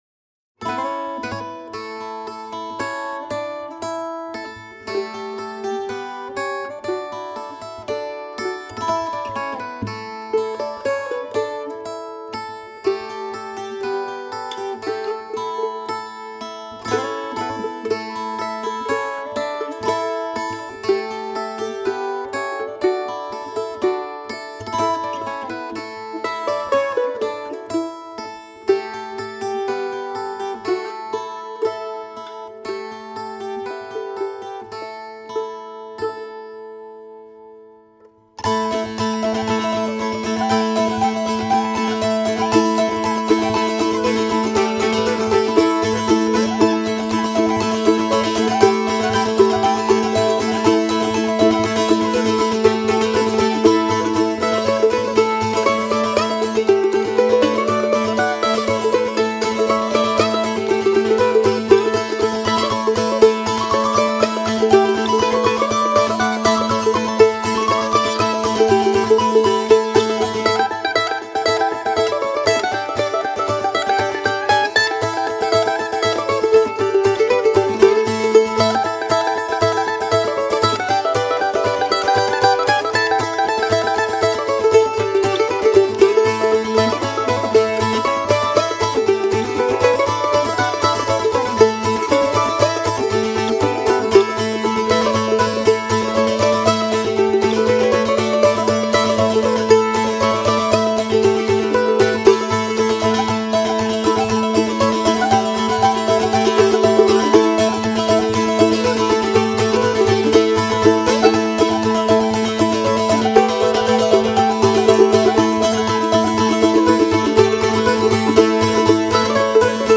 JUNE APPLE | MANDOLIN